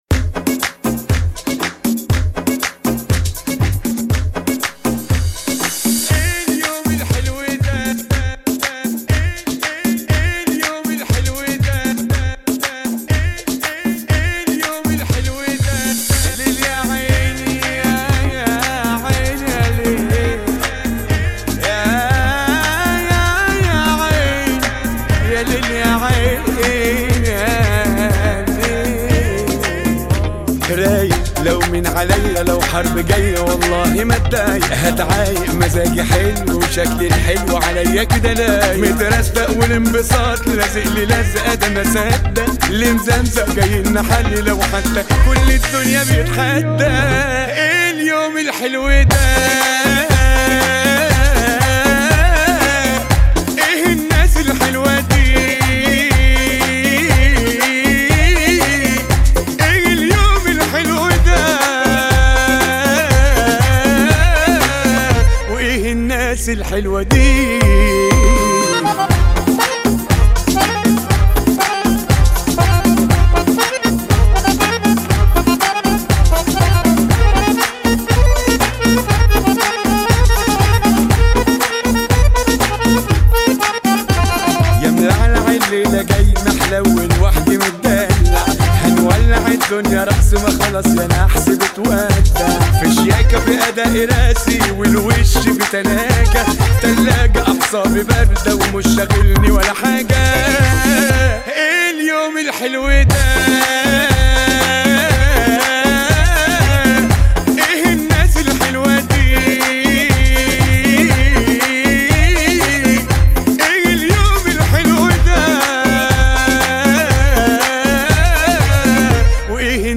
[ 120 bpm ] 2022